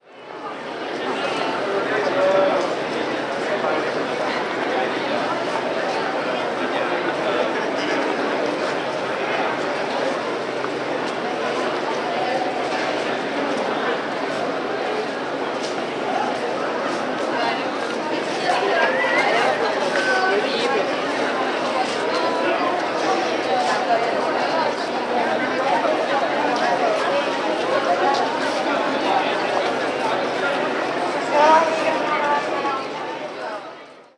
Ambiente de gente en una calle sin tráfico
barullo
jaleo
Sonidos: Gente
Sonidos: Voz humana
Sonidos: Ciudad